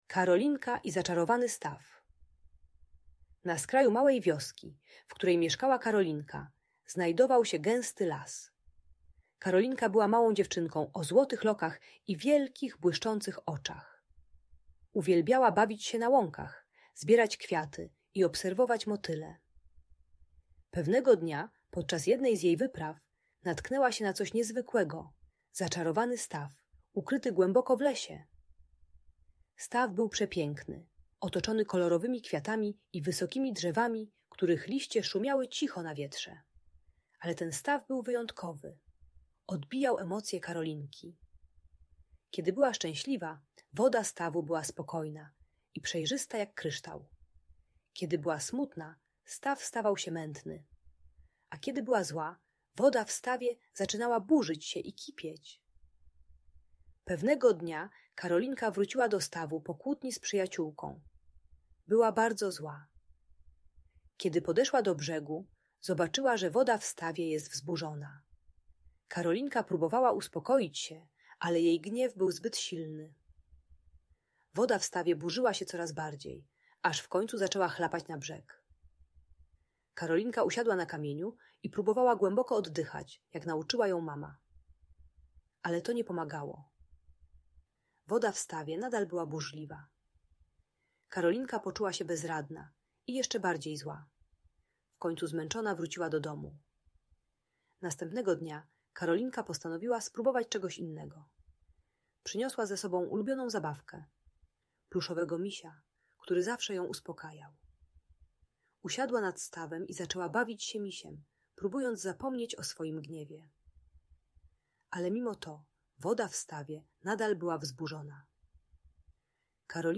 Karolinka i Zaczarowany Staw - Audiobajka